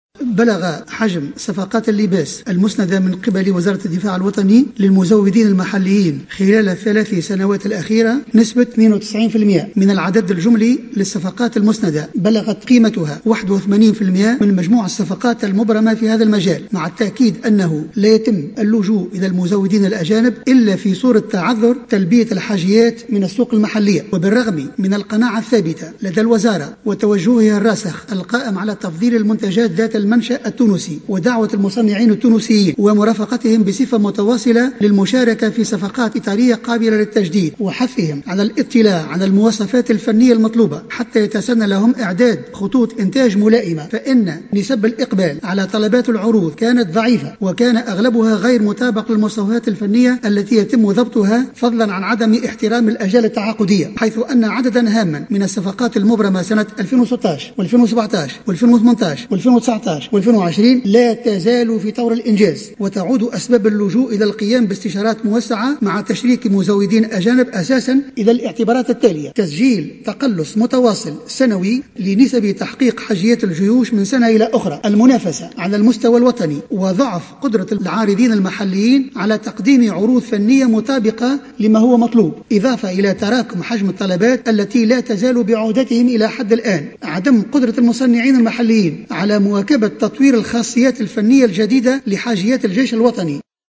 وأشار خلال جلسة حوار في البرلمان مع عدد من أعضاء الحكومة، إلى أنّه لا يتم اللجوء إلى المزودين الأجانب، إلاّ في صورة تعذر تلبية الحاجيات من السوق المحلية، مضيفا أنّ عدم احترام المُصّنعين المحليين للآجال التعاقدية، و ضعف نسب الإقبال على طلبات العروض التي كان أغلبها غير مطابق للمواصفات الفنية المضبوطة، وتراكم حجم الطلبات التي مازالت بعُهدتهم، وعدم قدرة المصنعين المحليين على مواكبة تطوير الخاصيات الفنية الجديدة لحاجيات الجيش الوطني، تُعّد من أسباب اللجوء إلى مزودين أجانب، حسب تعبيره.